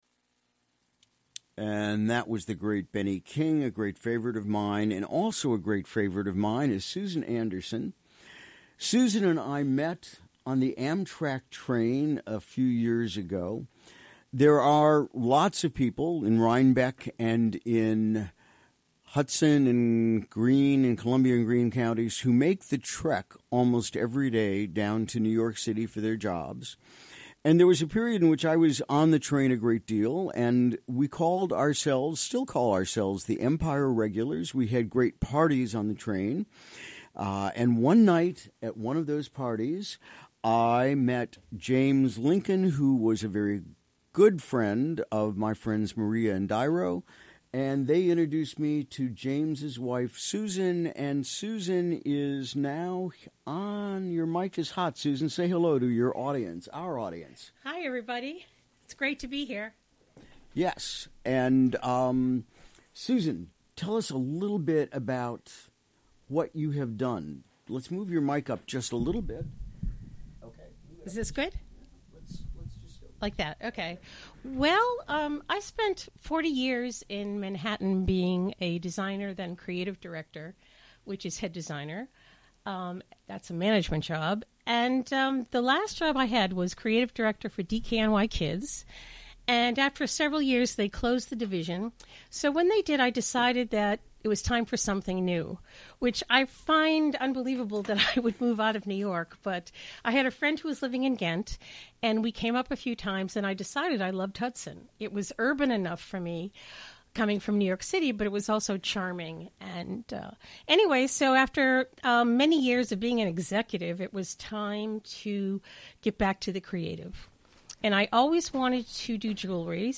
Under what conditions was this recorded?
Recorded during the WGXC Morning Show on Wednesday, April 19.